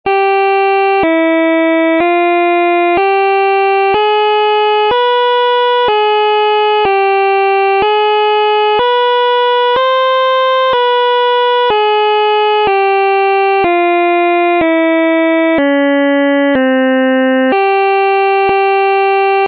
Κλίμακα
Οἱ ἤχοι ἔχουν παραχθεῖ μὲ ὑπολογιστὴ μὲ ὑπέρθεση ἀρμονικῶν.